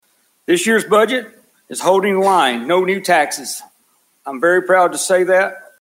Mayor Knight Delivers Hopkinsville Budget Address
Mayor James R. Knight Jr. presented his proposed 2025–2026 budget Friday at the City of Hopkinsville Municipal Center, featuring public safety, economic growth, city infrastructure, and support for municipal employees—while promising no new tax increases.